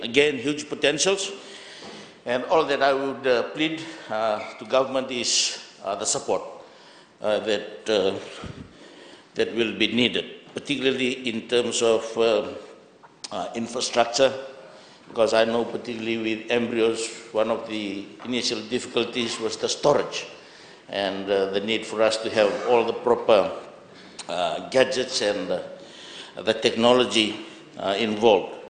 Leader of Opposition Inia Seruiratu